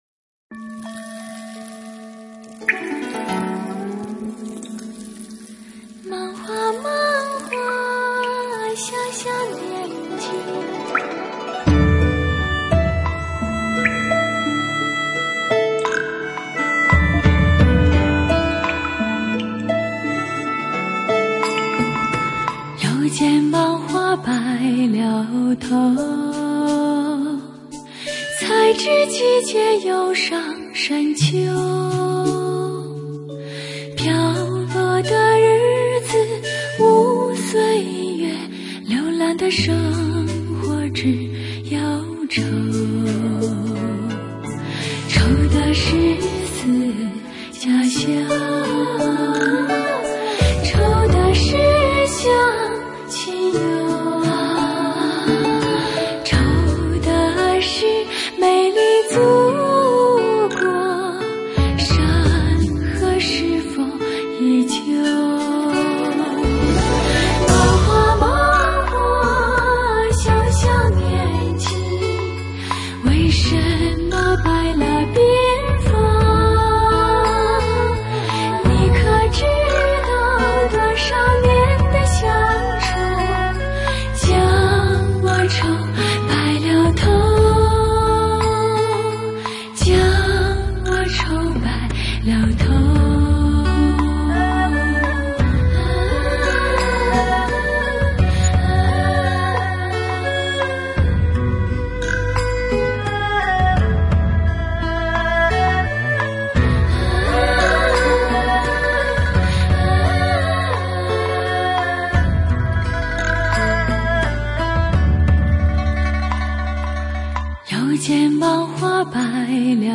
水晶般的声音 淡淡的感动 梦一样的朦胧
超然的构思 超广角音场空间 全新的音乐风格
极具透明度的音质 天然纯净的人声音色